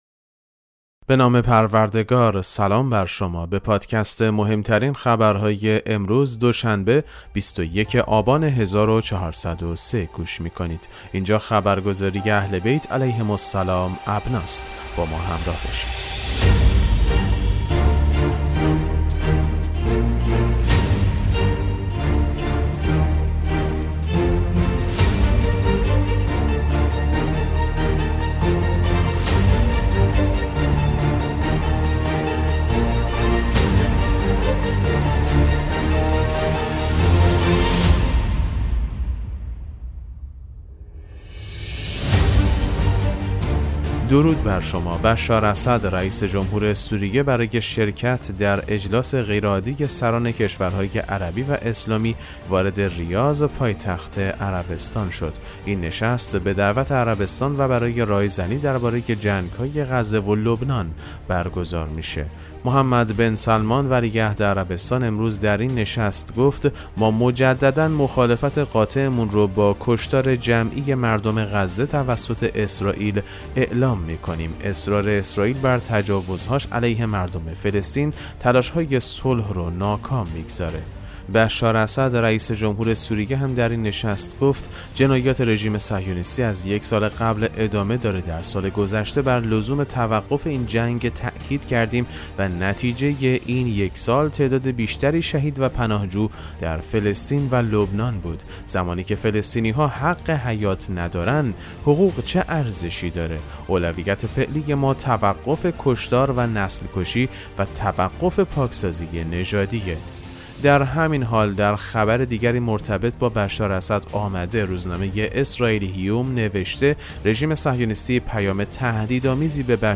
پادکست مهم‌ترین اخبار ابنا فارسی ــ 21 آبان 1403